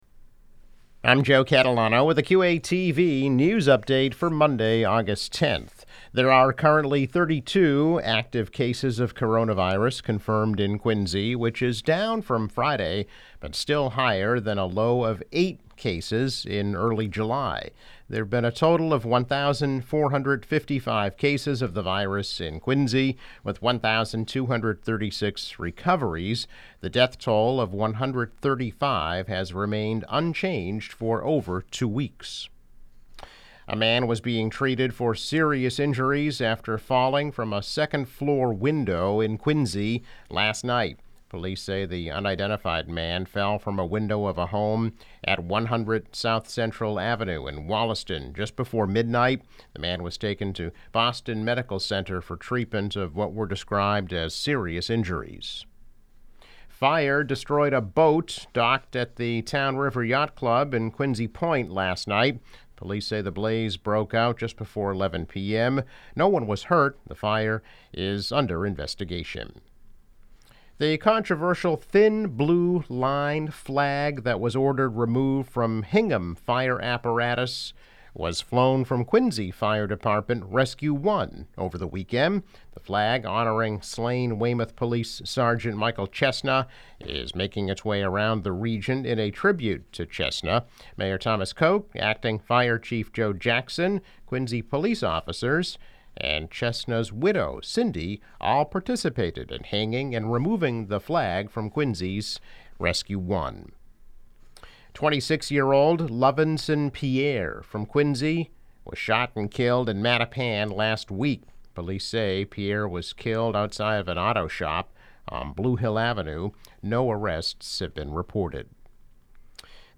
News Update - August 10, 2020